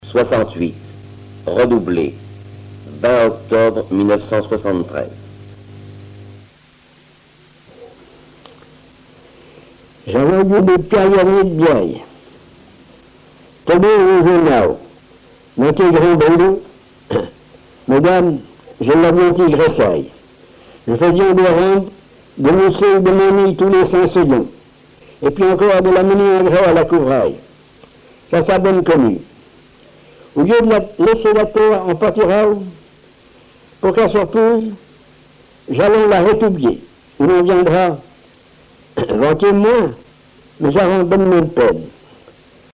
Patois local
Récits en patois
Catégorie Récit